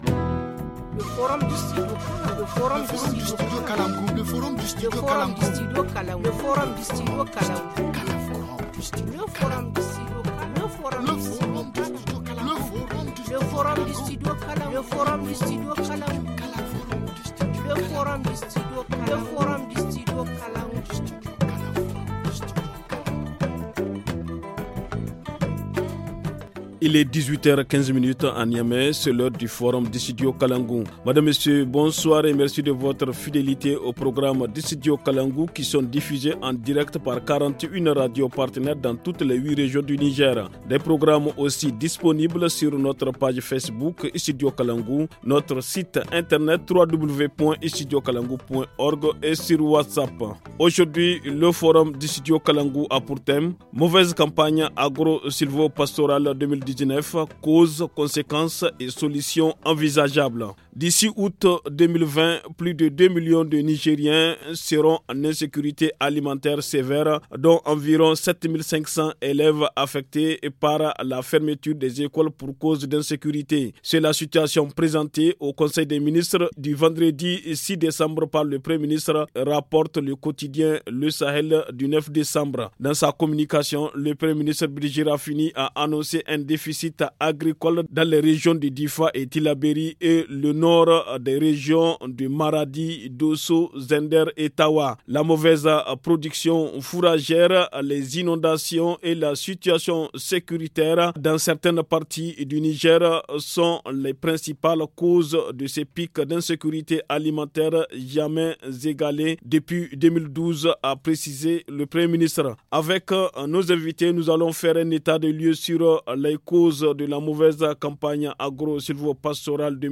Le forum en français